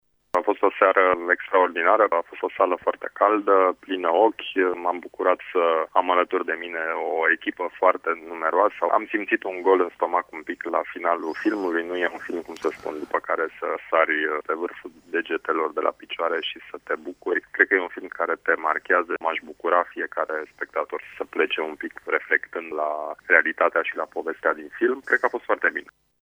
După proiecţie, acesta ne-a împărtăşit impresiile sale: